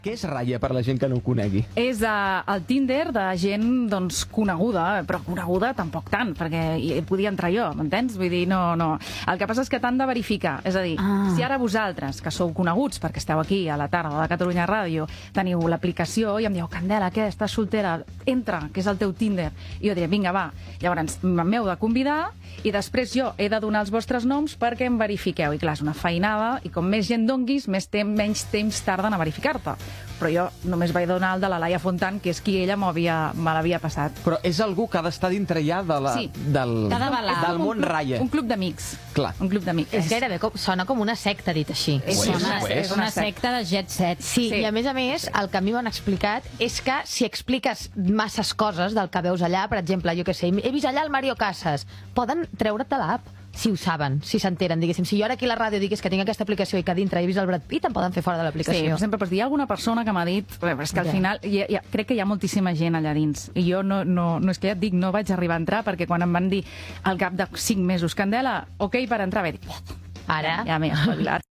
Entreteniment
Fragment extret del canal de Tiktok de Catalunya Ràdio